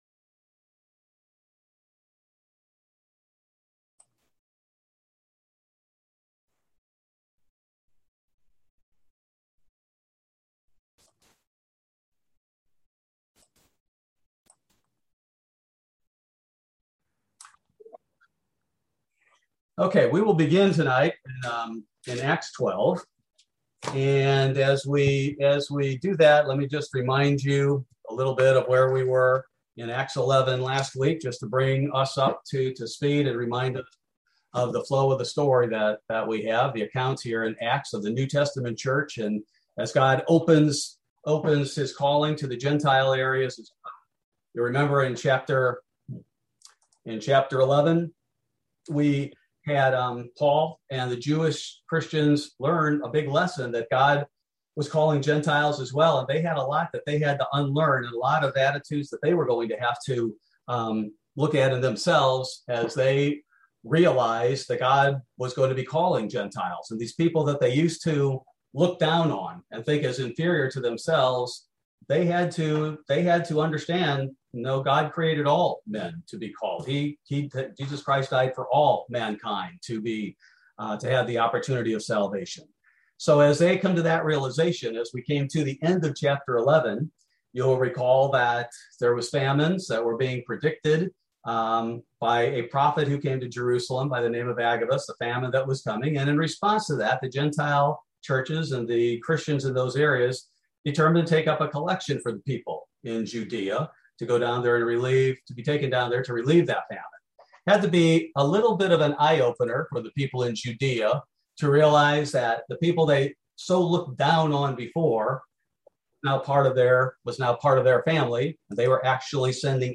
Bible Study: August 4, 2021